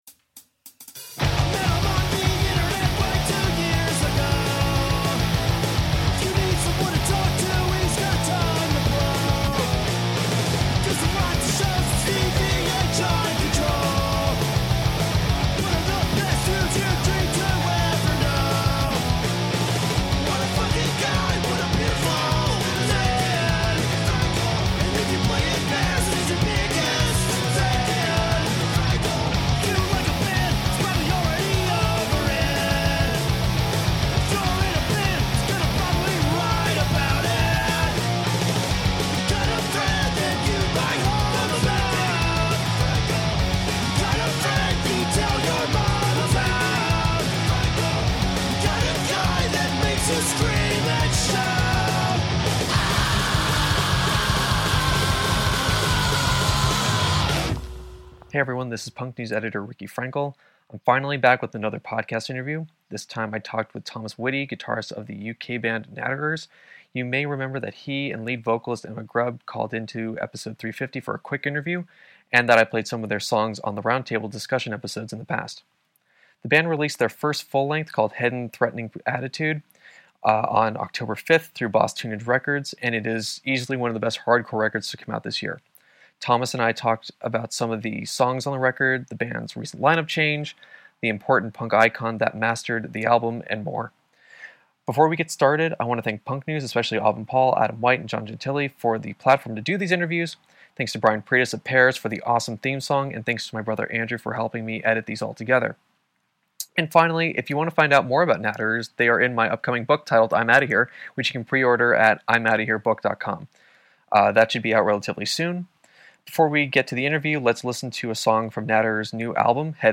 Bonus - Natterers Interview